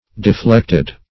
Deflected - definition of Deflected - synonyms, pronunciation, spelling from Free Dictionary
Deflected \De*flect"ed\, a.